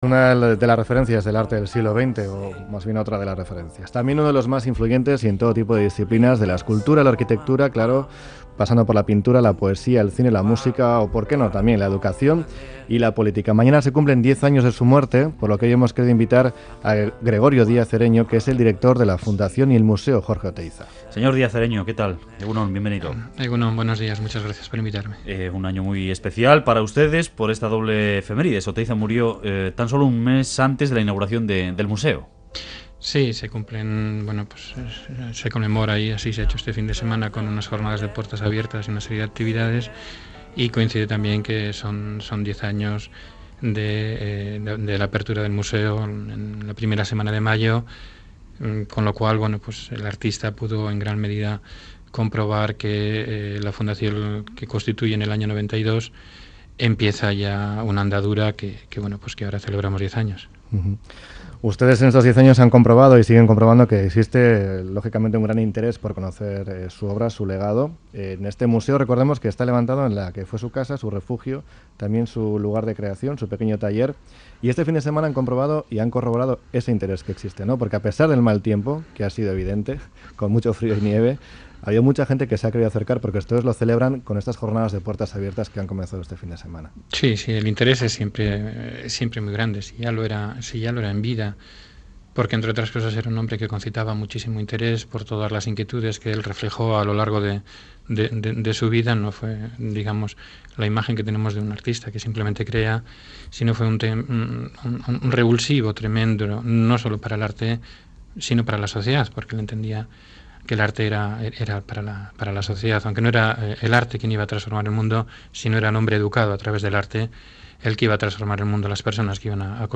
Radio Euskadi BOULEVARD Entrevista